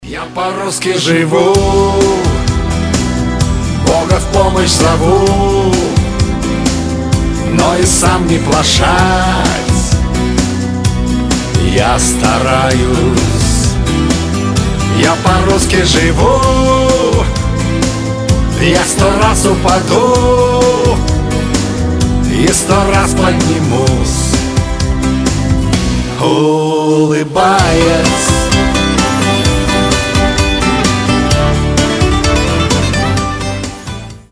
• Качество: 320, Stereo
позитивные
мотивирующие